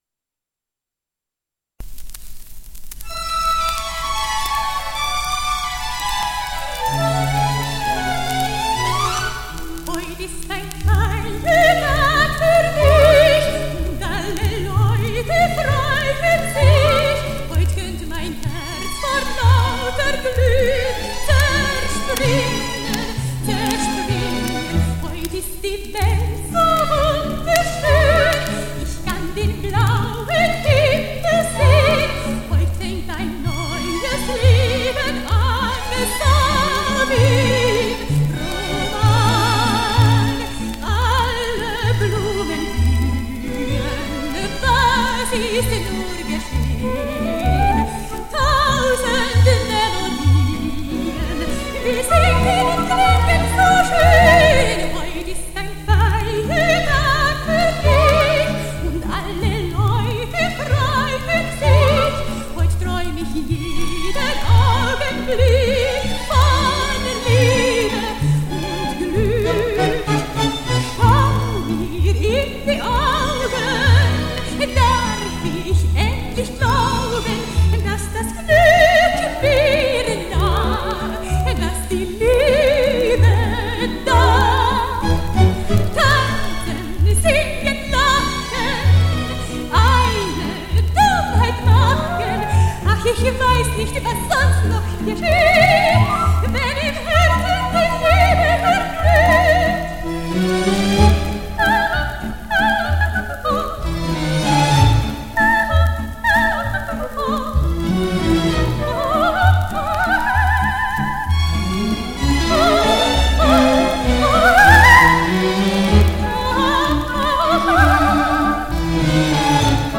Венгерская оперная певица. 14 марта 1914 – 16